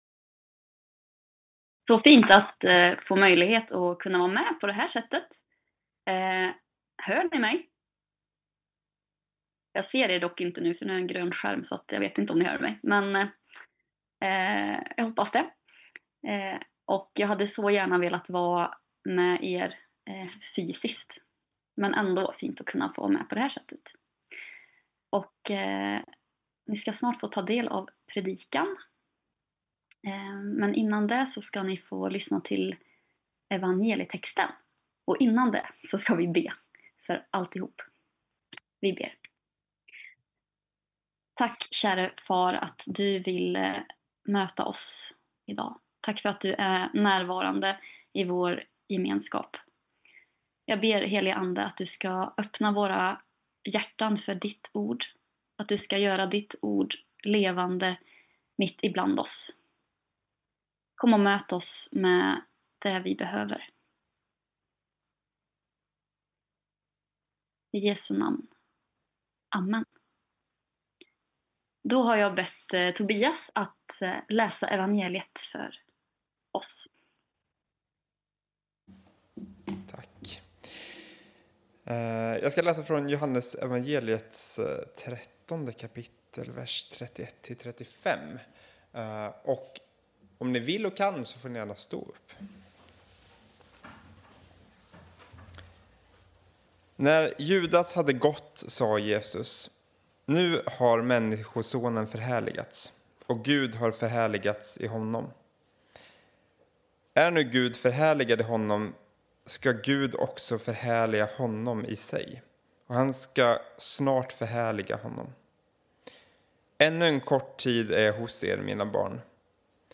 Predikan
Inspelad under gudstjänst i Equmeniakyrkan Väte 2026-04-26.
predikan gjordes via videolänk.)